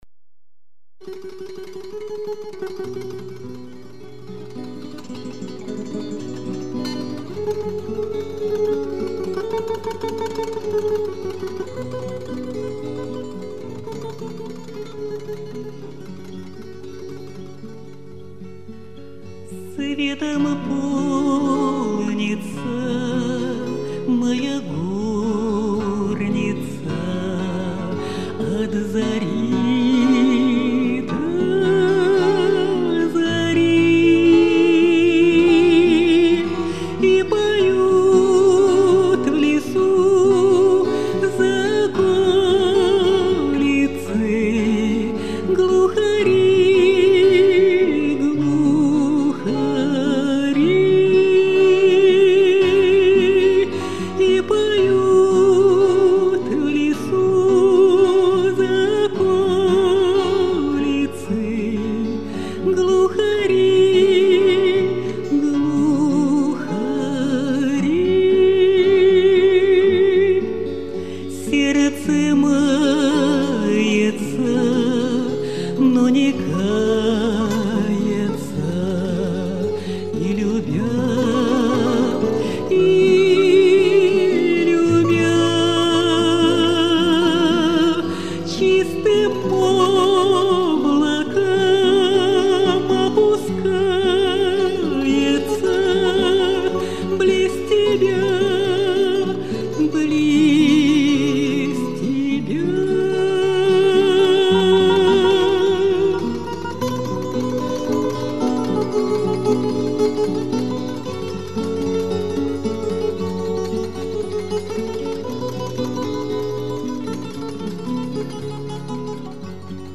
Духовная музыка